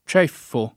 ©$ffo o ©%ffo] s. m. — sim. il cogn. Ceffi — pn. originaria con -e- aperta (dal fr. chef [š$f], ant. chief, «testa»), ancóra preval. a Firenze e Siena, ma in minoranza nel resto di Tosc. e del Centro davanti a una pn. con -e- chiusa difficile a spiegarsi (attraz. di ceppo?)